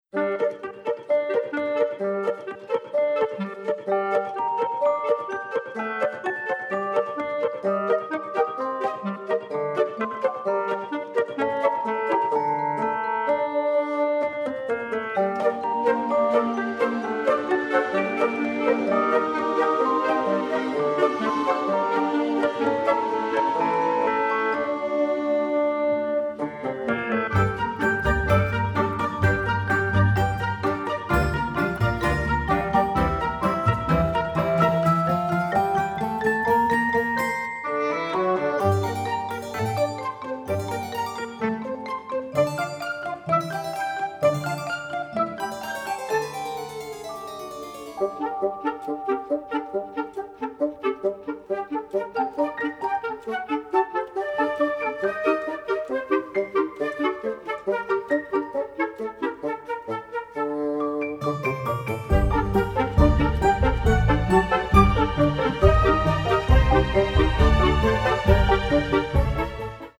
The music was recorded in magnificent sound in London